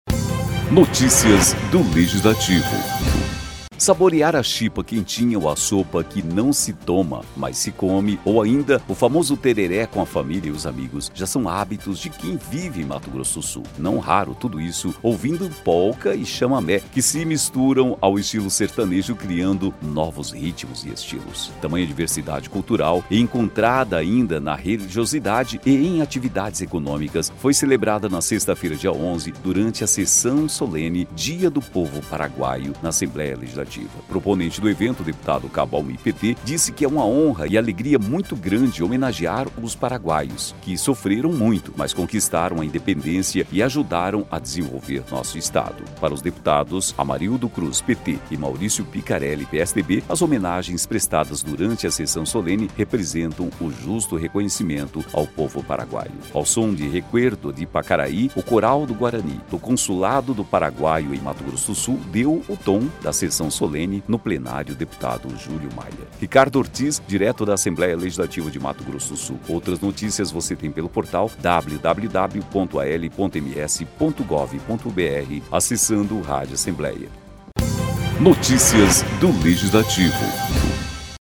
Tamanha diversidade cultural, encontrada ainda na religiosidade e em atividades econômicas, foi celebrada nesta sexta-feira (11), durante a sessão solene Dia do Povo Paraguaio, na Assembleia Legislativa.